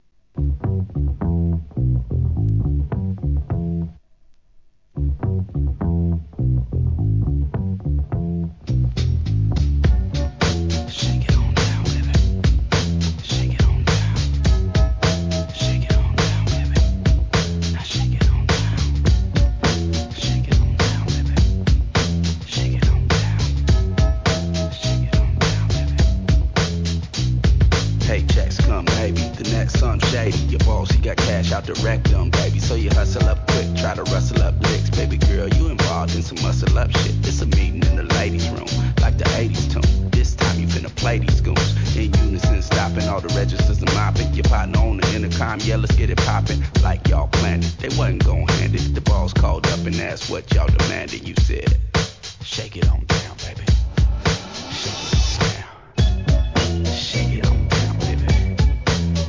HIP HOP/R&B
エスニックなパーカッションに「シャバダバダ〜♪」印象的なB/Wも!!